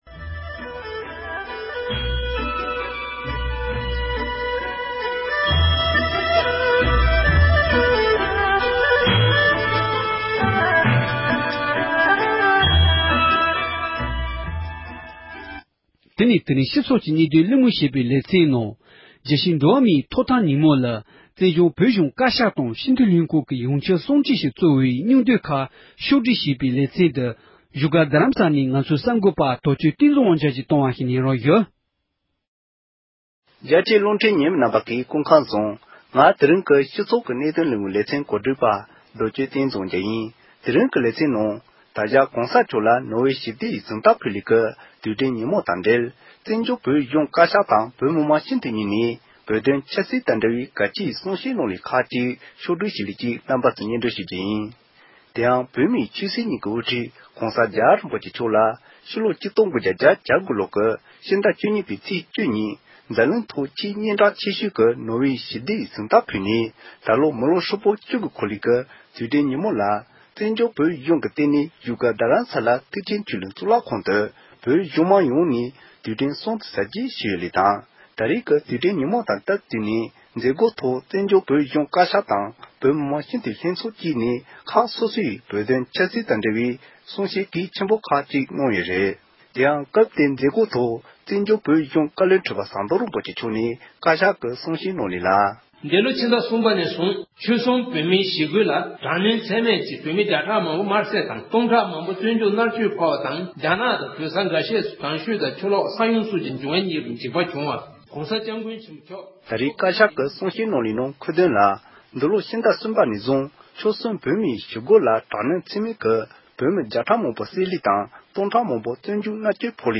བོད་དོན་ཆབ་སྲིད་དང་འབྲེལ་བའི་གསུང་བཤད།
བཙན་བྱོལ་བོད་གཞུང་བཀའ་ཤག་དང་བོད་མི་མང་སྤྱི་འཐུས་ལྷན་ཚོཌ་ངོས་ནས་བོད་དོན་ཆབ་སྲིད་དང་འབྲེལ་བའི་གལ་ཆེའི་གསུང་བཤད་གནང་བ།